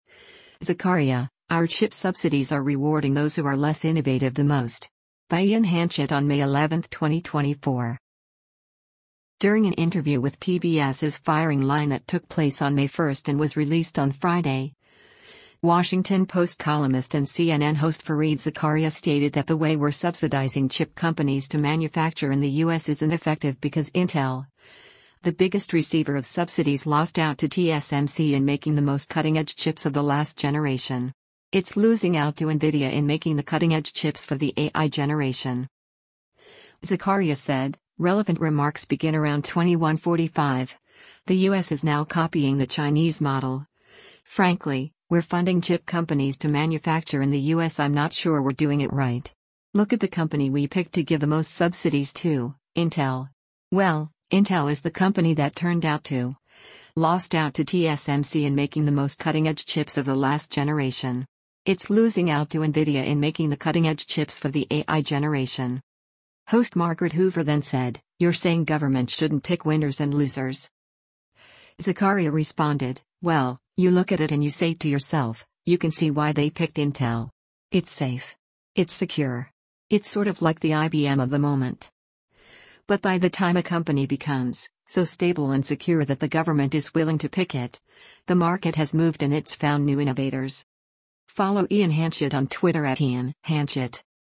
During an interview with PBS’s “Firing Line” that took place on May 1 and was released on Friday, Washington Post columnist and CNN host Fareed Zakaria stated that the way we’re subsidizing chip companies to manufacture in the U.S. isn’t effective because Intel, the biggest receiver of subsidies “lost out to TSMC in making the most cutting-edge chips of the last generation.